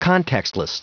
Prononciation du mot contextless en anglais (fichier audio)